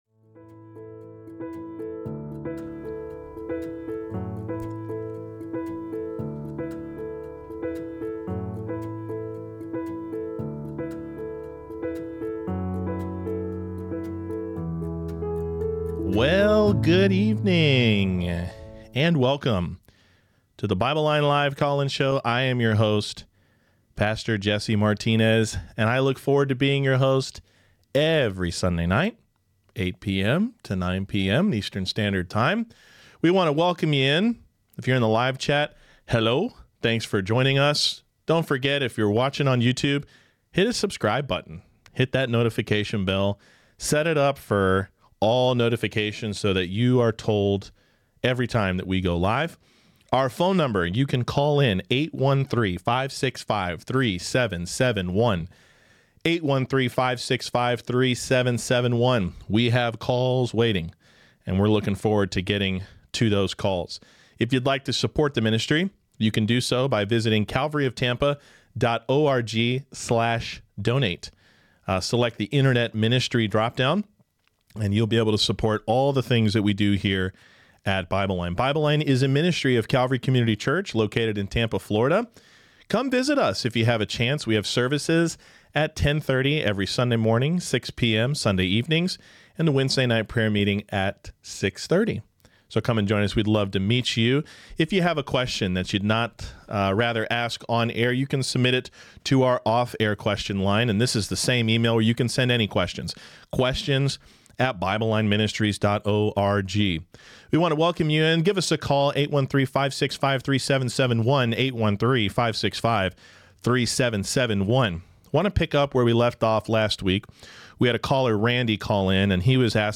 BibleLine LIVE QNA Replay | Emptiness, Apostasy, Phil. 3:18, Fallen Angels, and MORE!!!